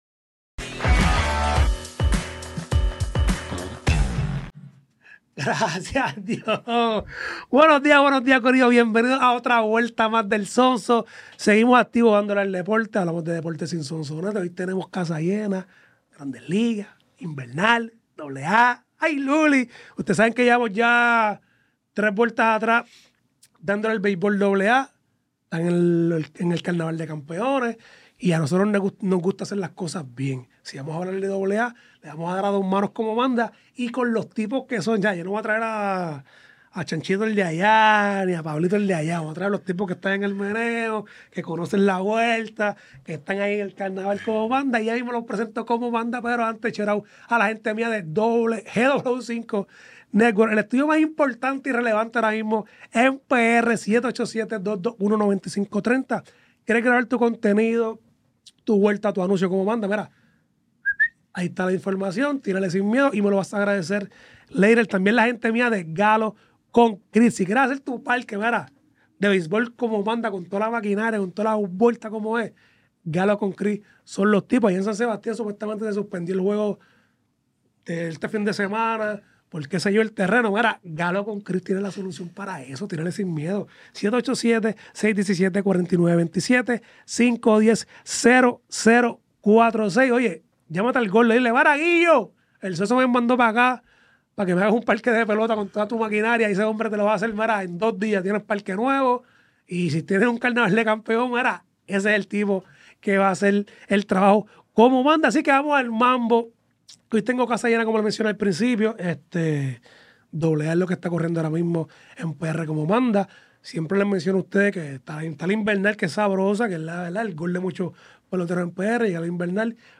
Grabado en GW5 Studio